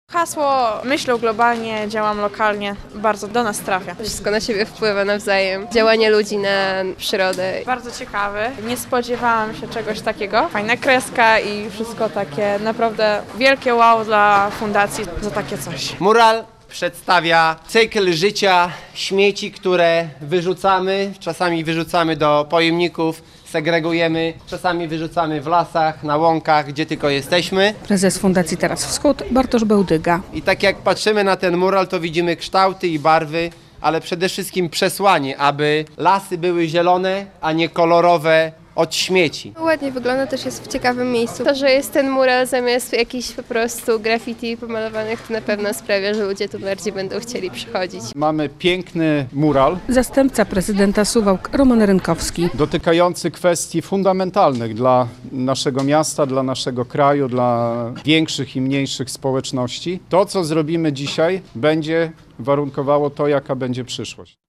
relacja
Roman Rynkowski, zastępca prezydenta Suwałk, dodaje, że mural zwraca uwagę na poważny temat.